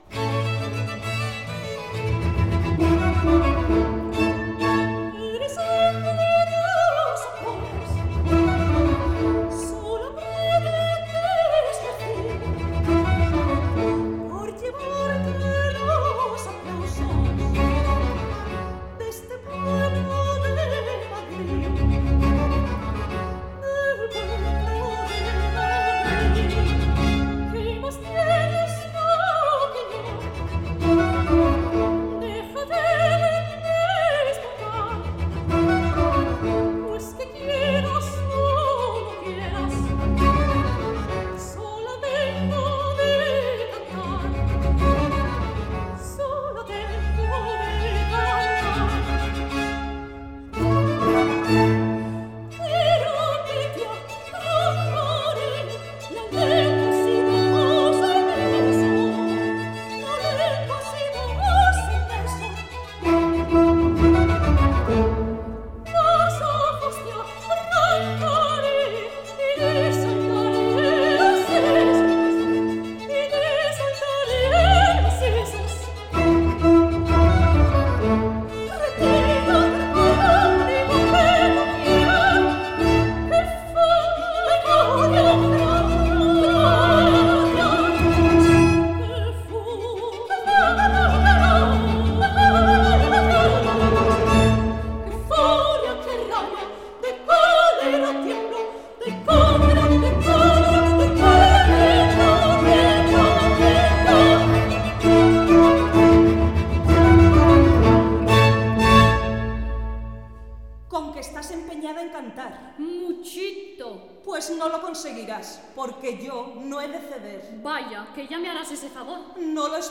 sopranos
17_la_competencia_de_las_dos_hermanas_no__3_copla_allegro.mp3